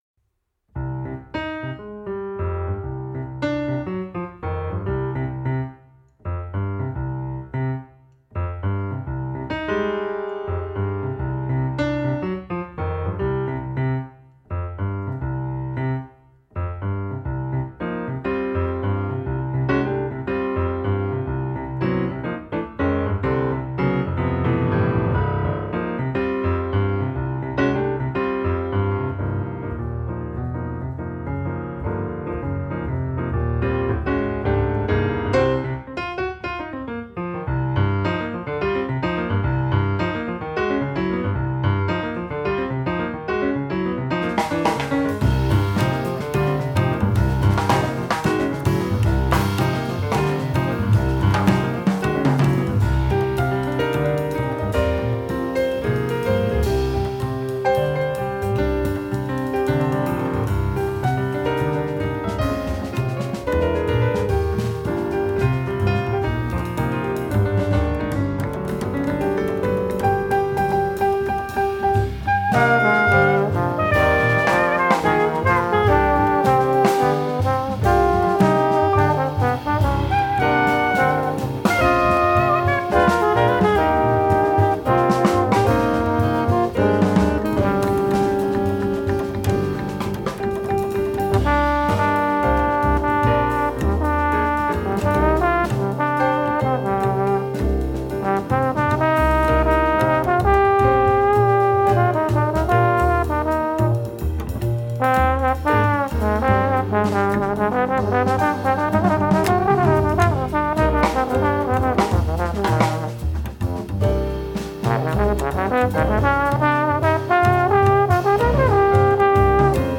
pianiste
Influences tantôt jazz, tantôt latines